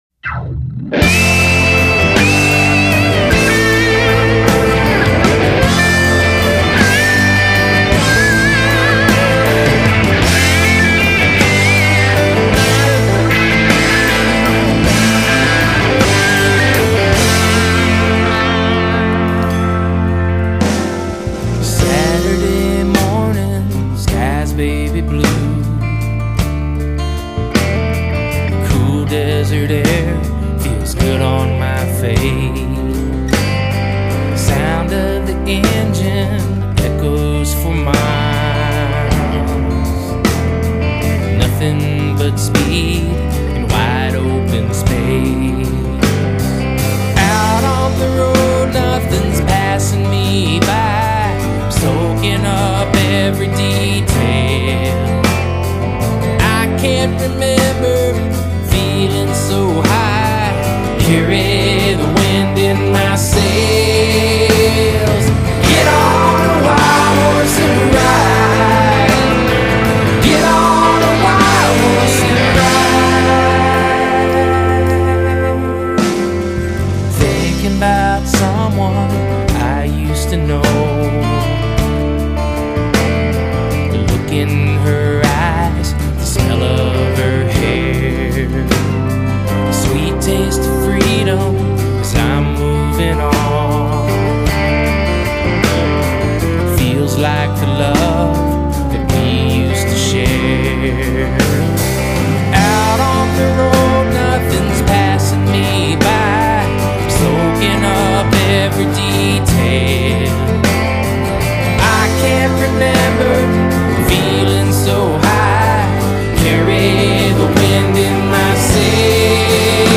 Lead vocal, acoustic guitar
Backing vocals
Bass
Drums
Percussion
Pedal steel